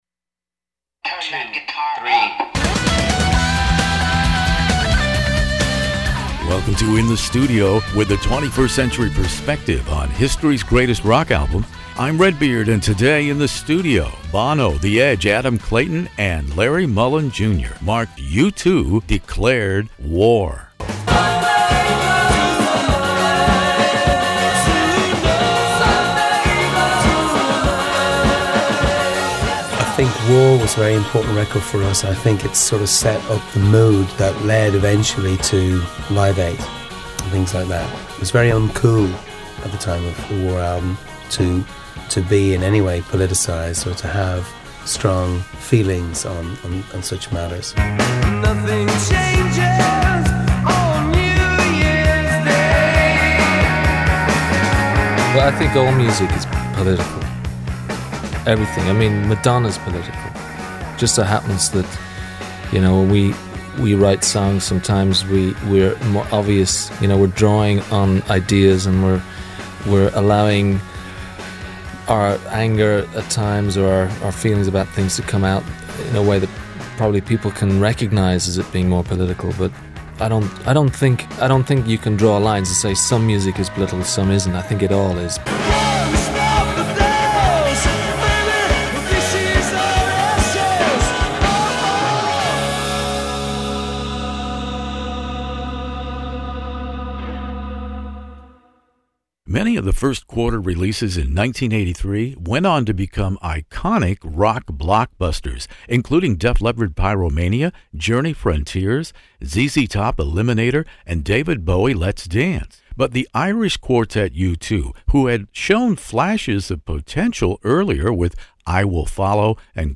U2 "War" interview with Bono, The Edge, Adam Clayton, Larry Mullen jr